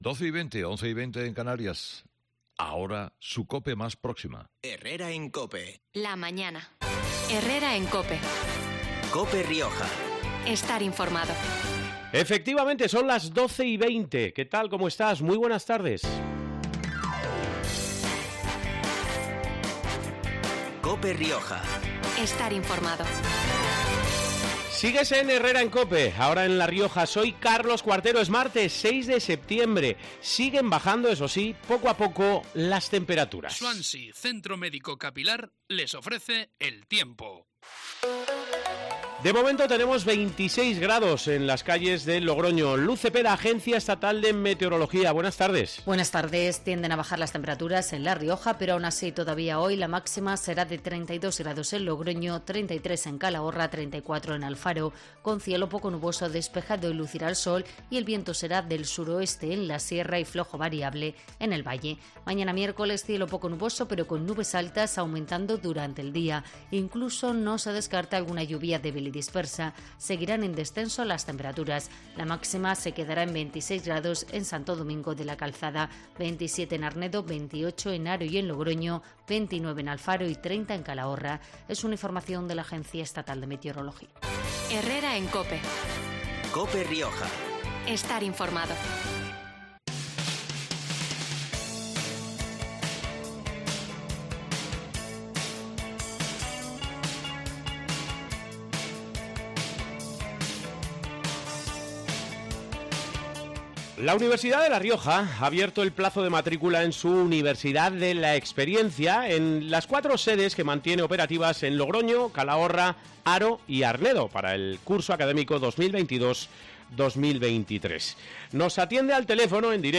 en los micrófonos de Cope Rioja